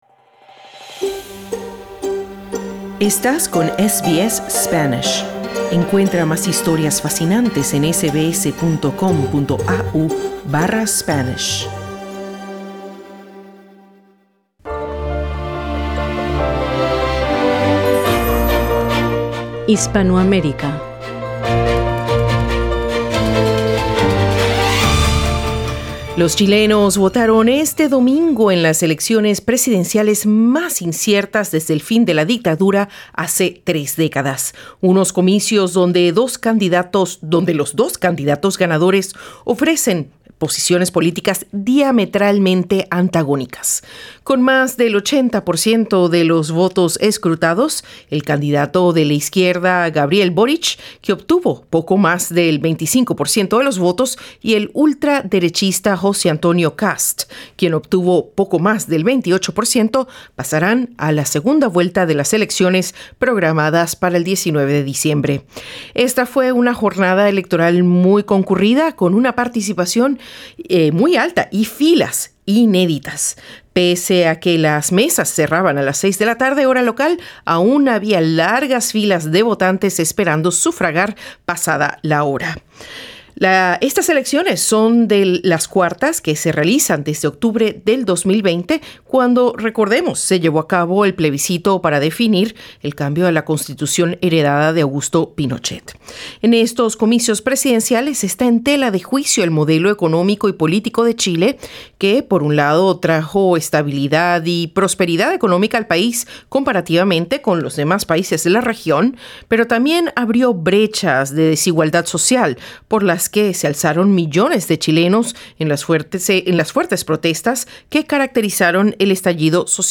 SBS Spanish conversó con chilenos que sufragaron en Australia